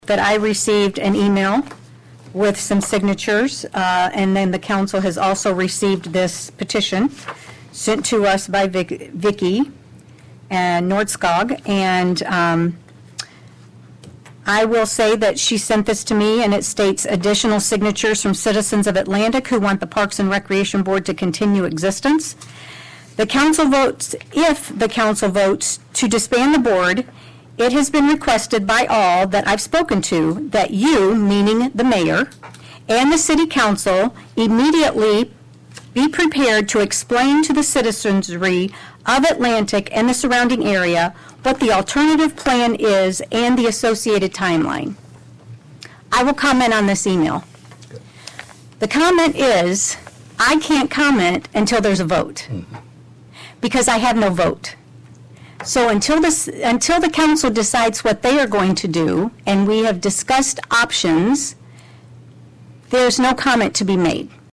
Atlantic Mayor Grace Garrett read an email with a petition against dissolving the Parks and Rec. Board.